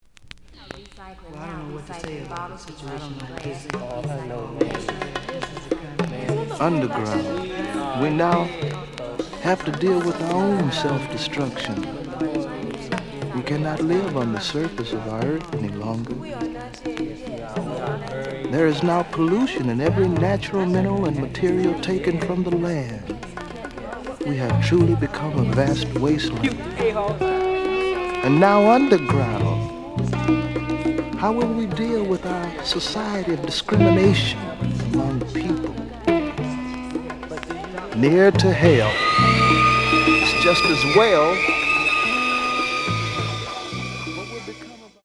The audio sample is recorded from the actual item.
●Genre: Soul, 70's Soul
Slight damage on both side labels. Plays good.)